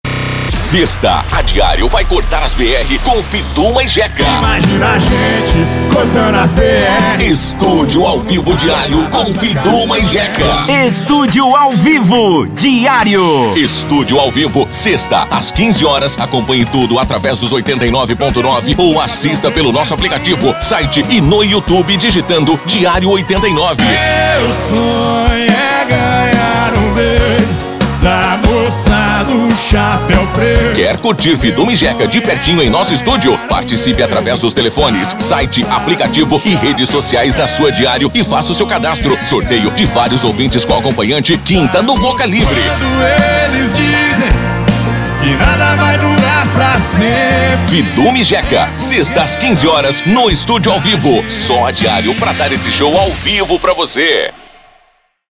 VOZES MASCULINAS
Estilos: Padrão Impacto